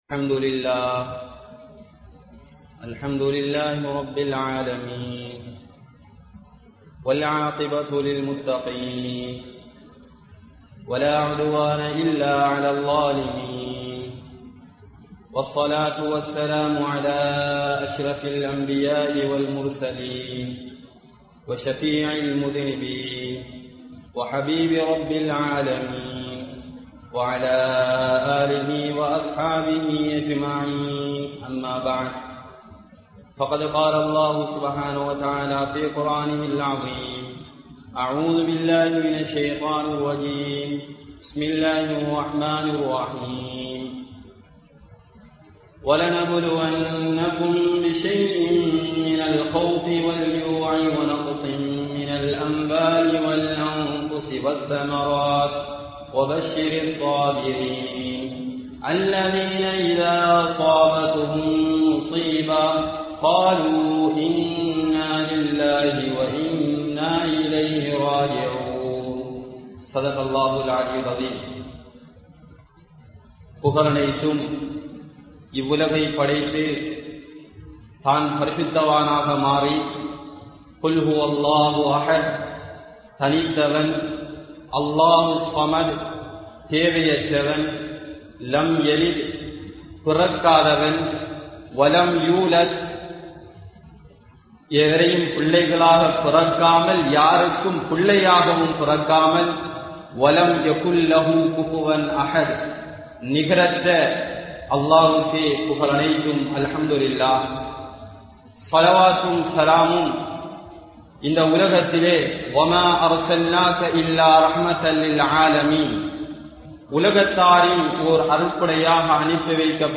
Maranam (மரணம்) | Audio Bayans | All Ceylon Muslim Youth Community | Addalaichenai